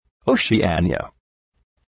Προφορά
{,əʋʃı’eınıə}